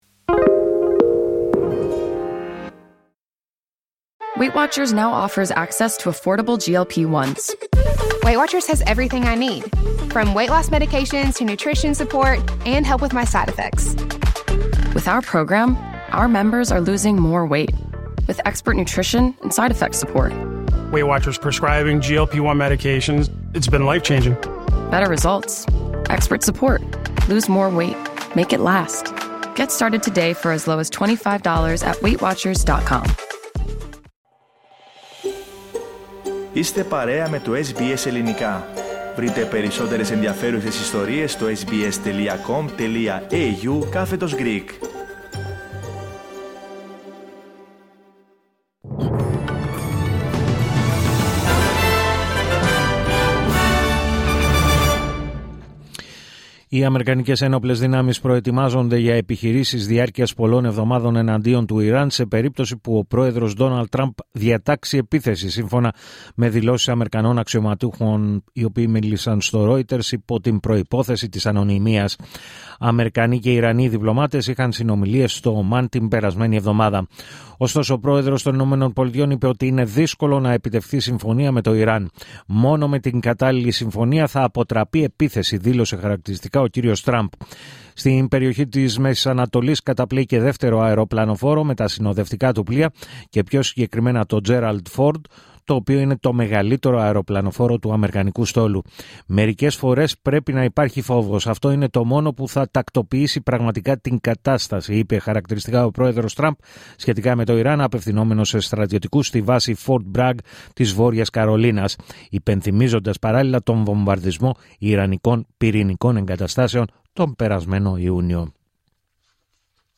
Δελτίο Ειδήσεων Σάββατο 14 Φεβρουαρίου 2026